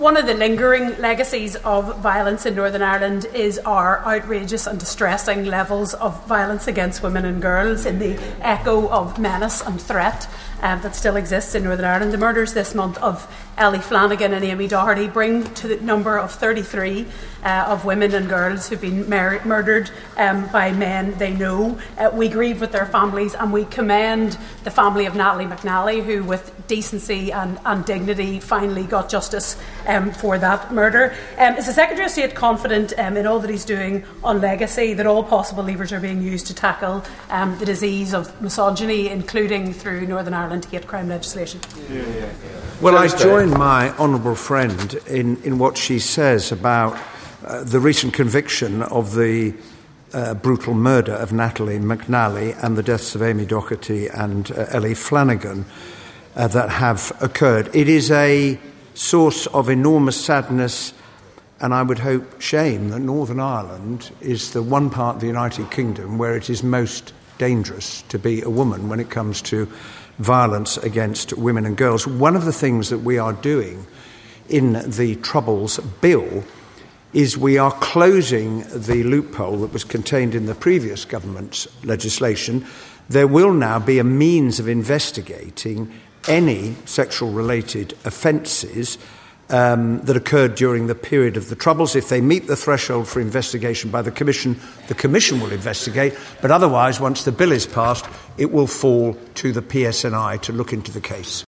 Northern Ireland Secretary Hilary Benn has told the House of Commons that Northern Ireland is the most dangerous part of the UK in which to be a woman.
Ms Hanna told MPs that the high level of violence against women and girls is one of the legacies of Northern Ireland’s troubled history……..